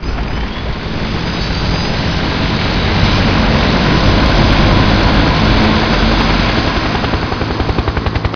heli_startup.ogg